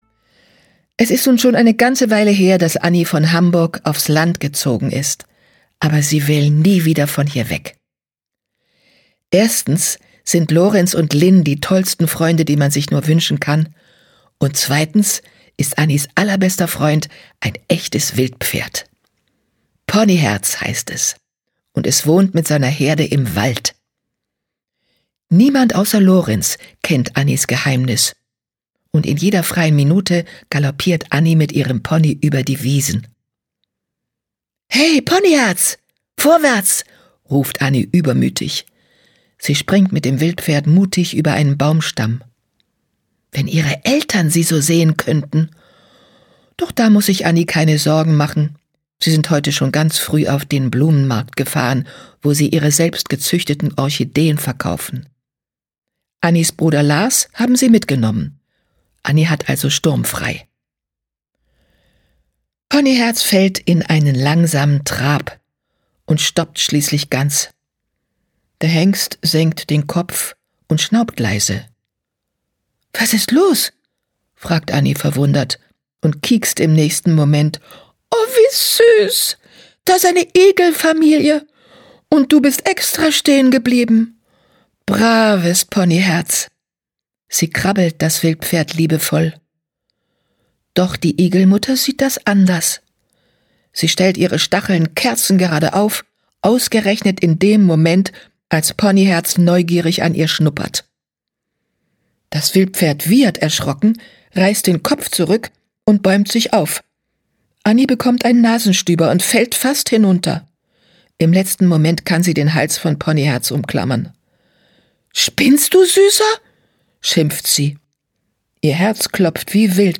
Sprecher
Schlagworte Bibi und Tina • Hörbuch • Hörbuch ab 6 • Hörbuch für Kinder • Kinderhörbuch • Meer • Ostwind • Pferde • Pferdegeschichten • Pferdehörbuch • Ponyherz • Ponyhof • Strand • Strandurlaub • Wendy • Wildpferd